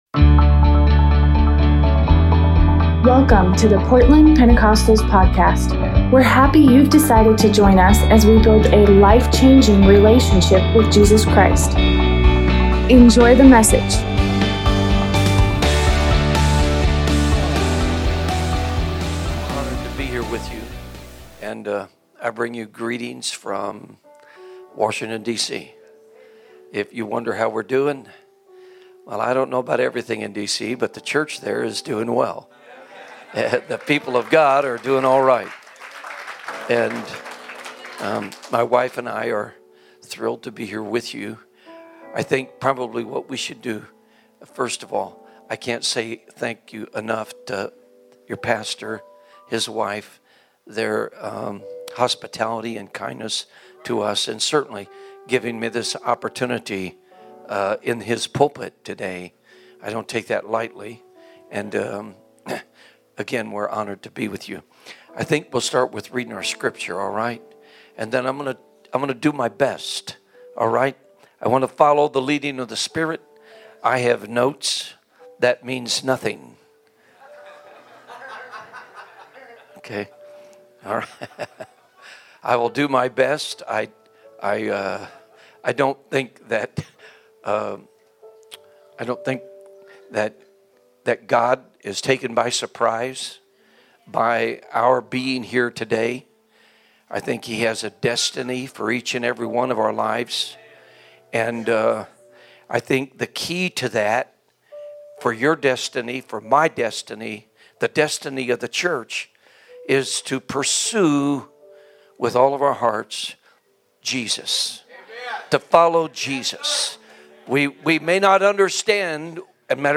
Sunday morning semon